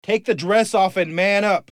舞蹈循环
描述：我前段时间做的一个有趣的、轻浮的曲子的一部分，我挖出了一个早期版本的伴奏，供我的自由声学同伴创造性地使用。
标签： 舞蹈 循环 古怪
声道立体声